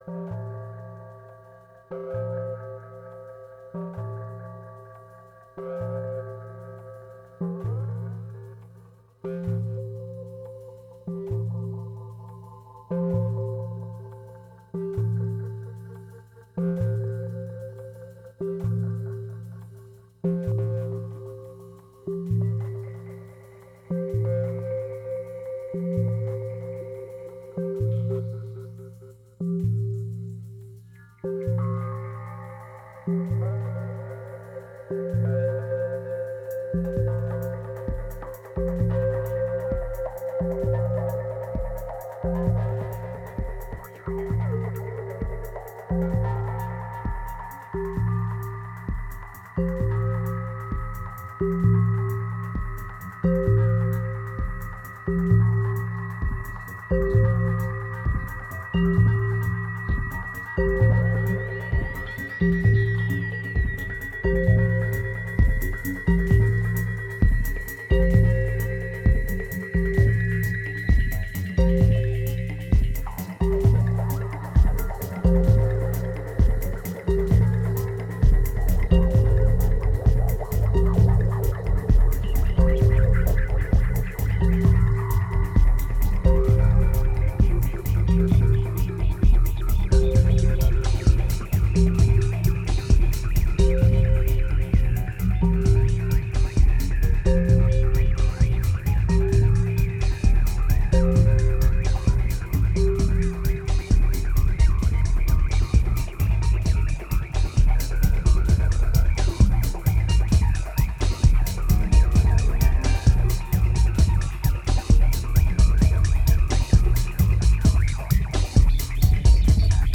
It's only autumn but the mood is already pretty somber.
1903📈 - 0%🤔 - 131BPM🔊 - 2010-10-03📅 - -139🌟